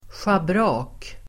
Ladda ner uttalet
Uttal: [sjabr'a:k]
schabrak.mp3